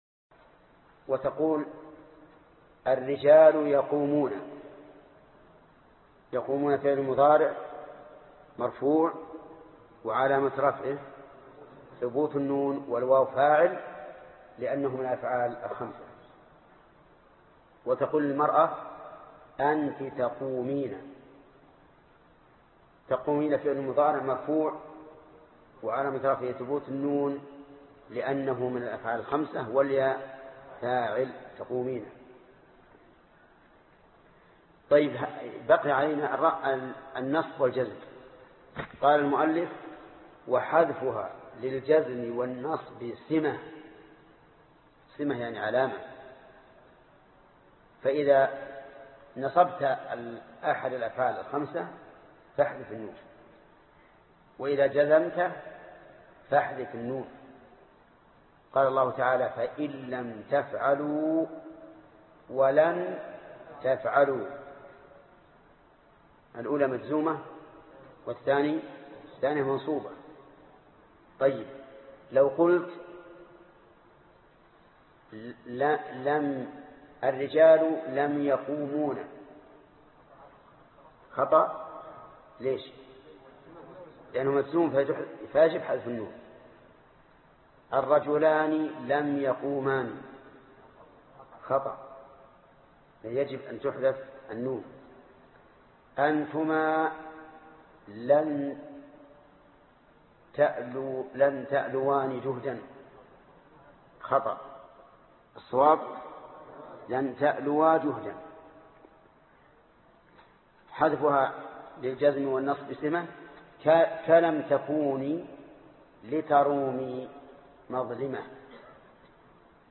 الدرس 39 المعرب والمبنى28- الابيات 44 و 45 (شرح الفية ابن مالك) - فضيلة الشيخ محمد بن صالح العثيمين رحمه الله